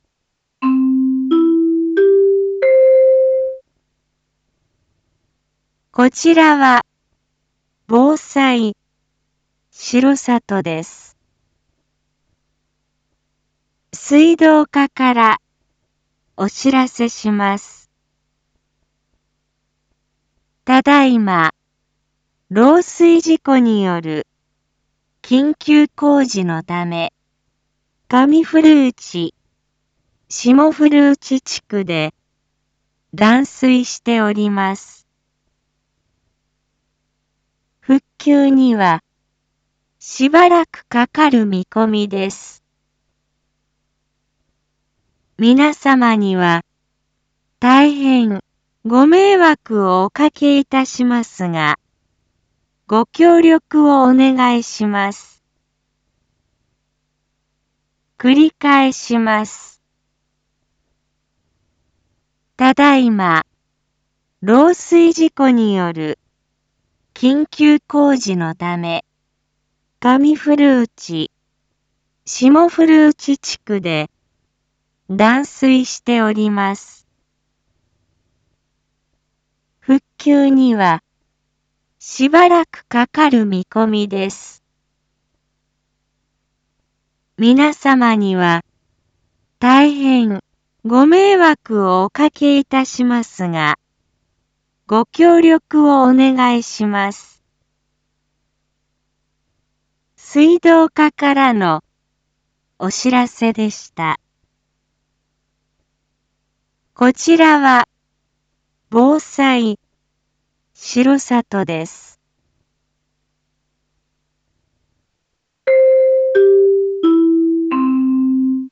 一般放送情報
Back Home 一般放送情報 音声放送 再生 一般放送情報 登録日時：2021-12-15 09:27:00 タイトル：R3.12.15 緊急放送分 インフォメーション：こちらは防災しろさとです 水道課からお知らせします ただいま、漏水事故による緊急工事のため 上古内、下古内地区で断水しております。